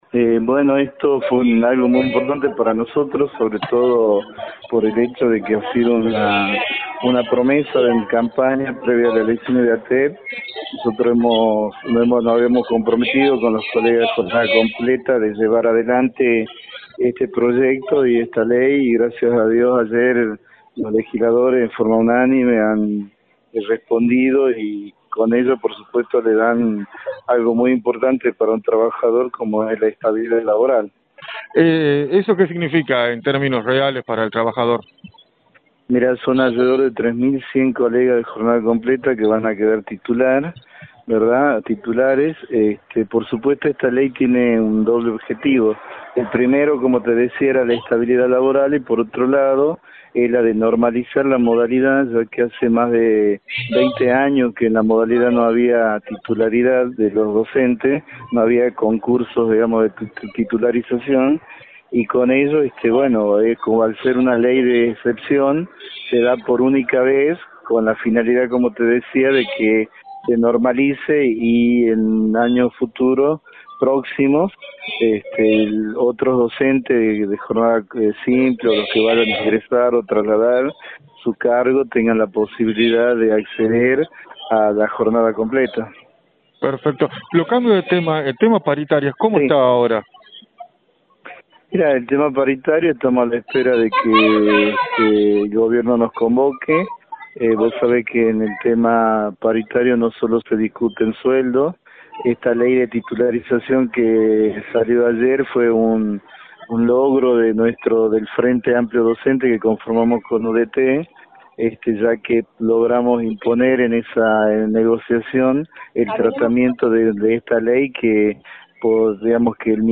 remarcó en Radio del Plata Tucumán, por la 93.9
entrevista